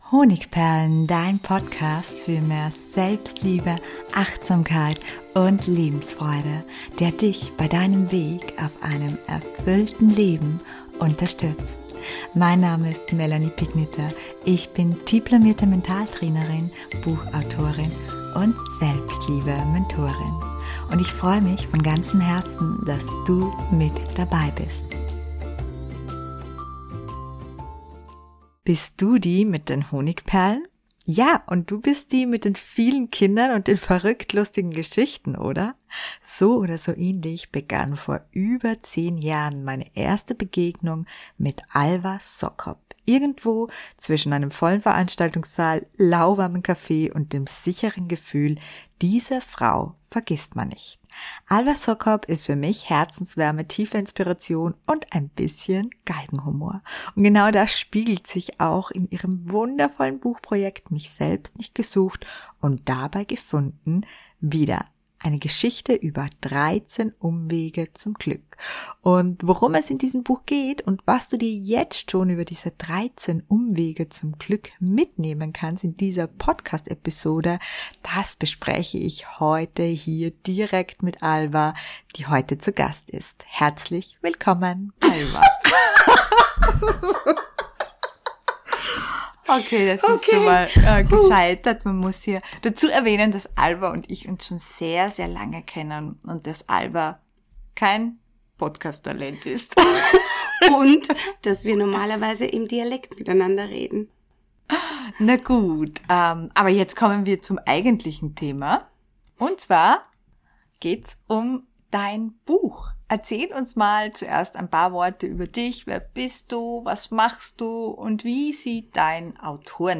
In dieser besonderen Podcast-Folge spreche ich mit Autorin